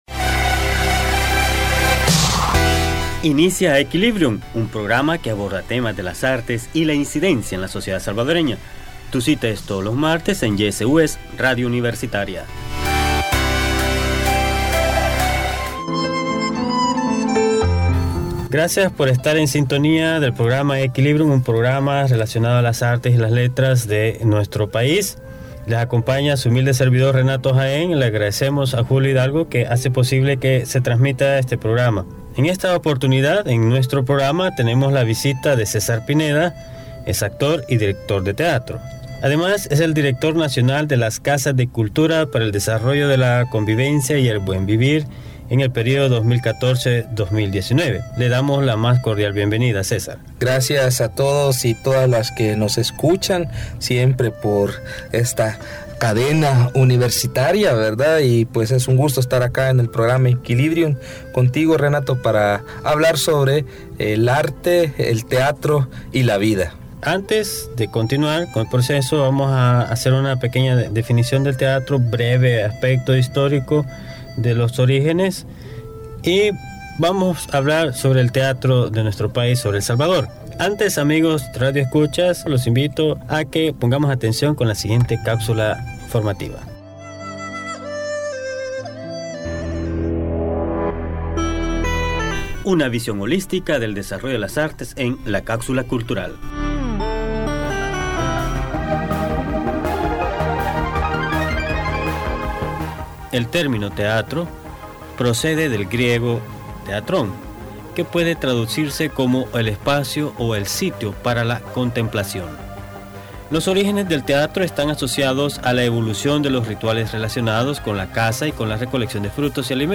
Entrevista programa Aequilibrium (26 mayo 2015): El teatro salvadoreño y las casas de la cultura